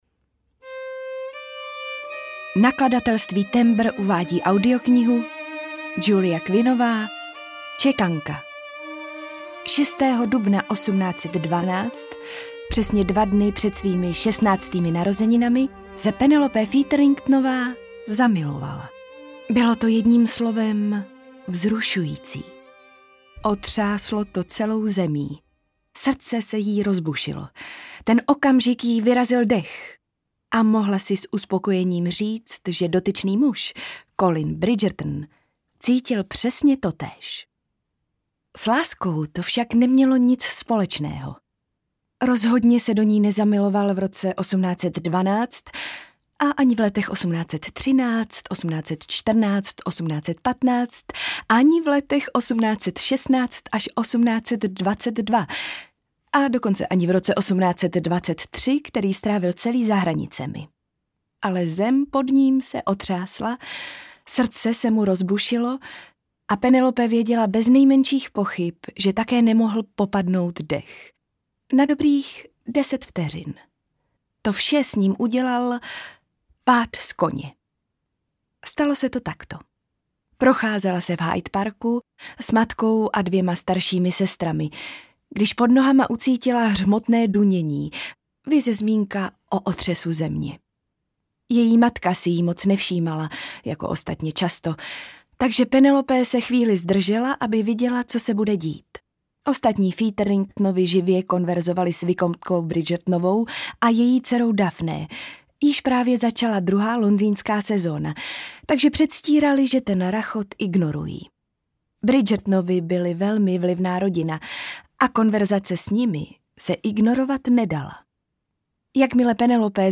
Čekanka audiokniha
Ukázka z knihy
cekanka-audiokniha